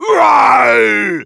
bull_ulti_vo_04.wav